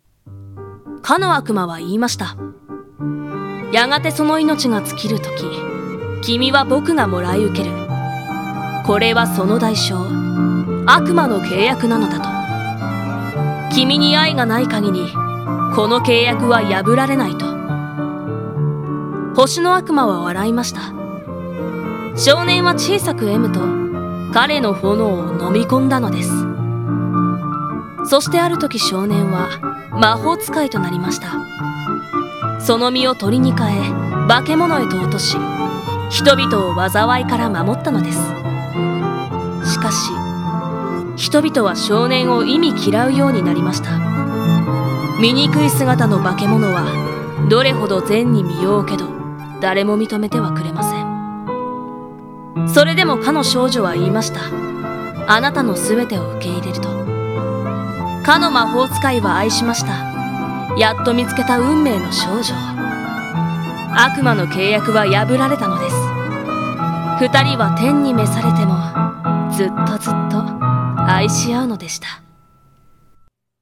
【一人声劇】星の悪魔